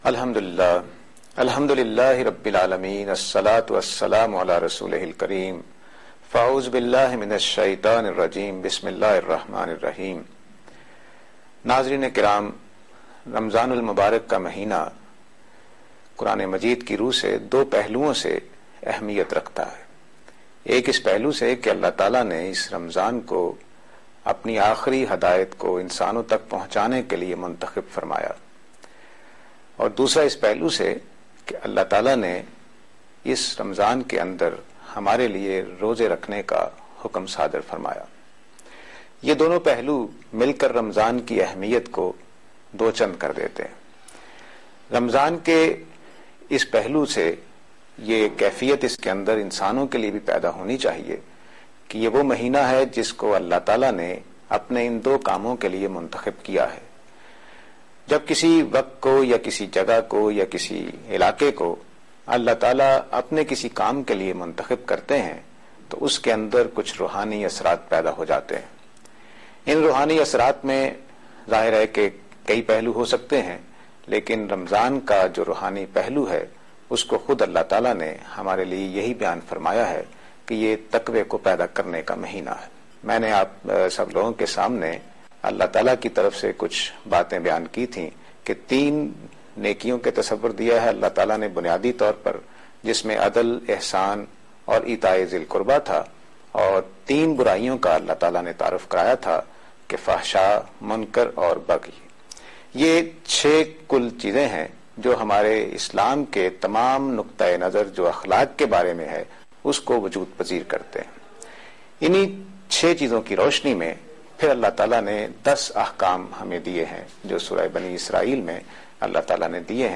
A short talk
Program Tazkiya-e-Ikhlaq on Aaj Tv.